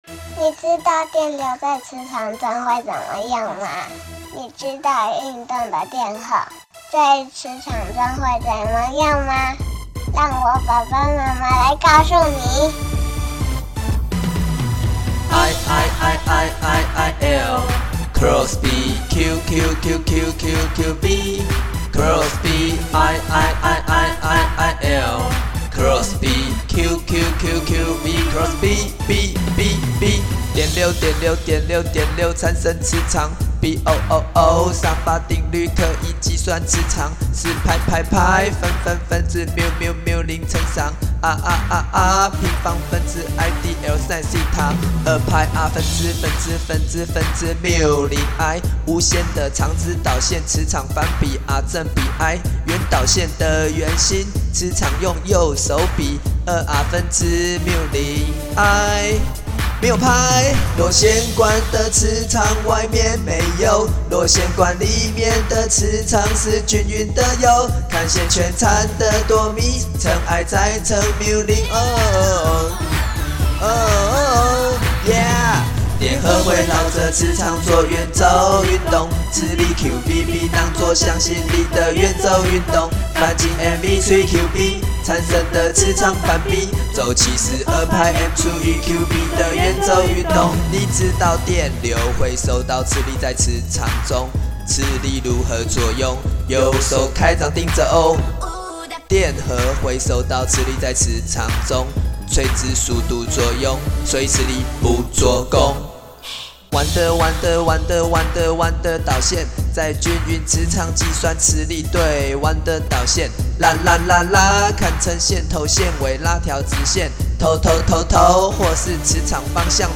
自編自唱歌曲(2017.02) [歌曲下載]